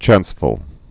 (chănsfəl)